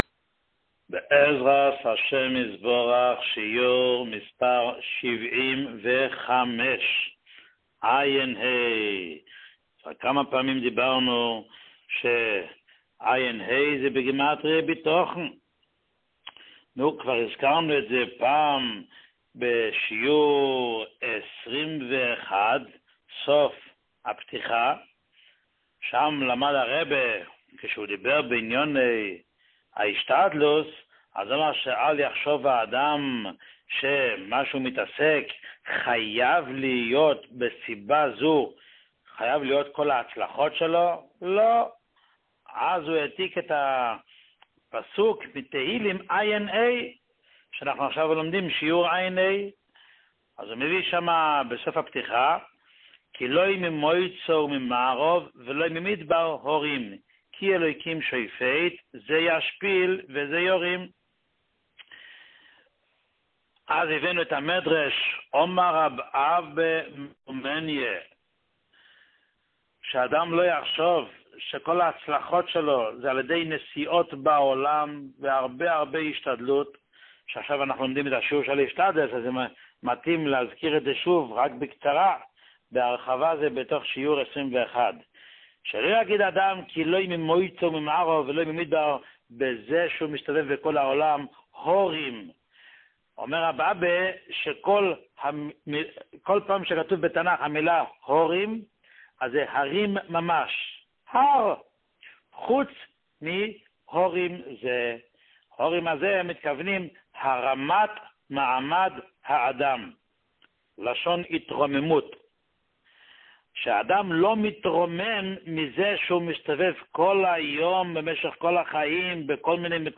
שיעור 75